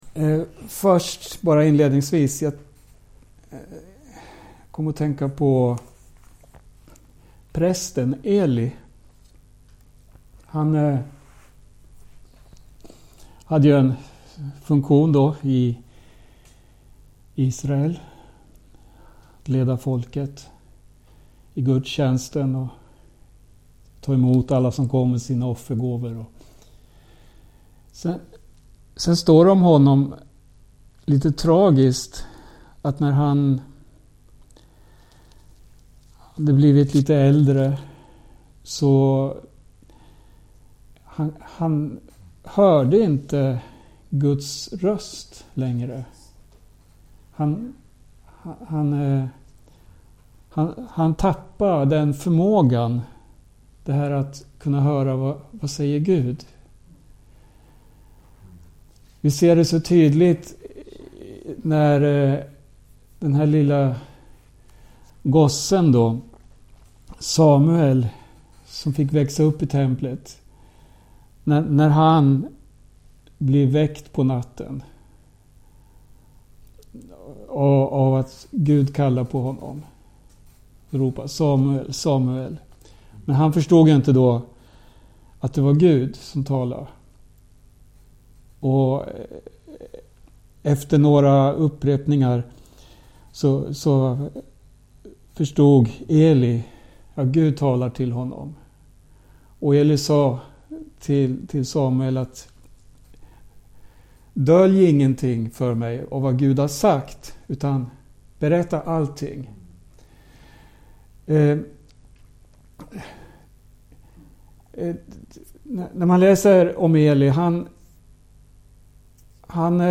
Bibelstudium